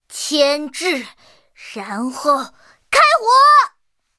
M4A3E2小飞象开火语音2.OGG